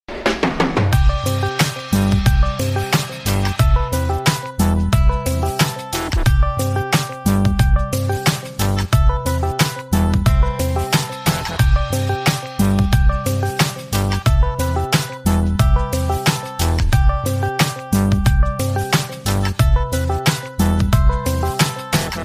Discord Rare Sound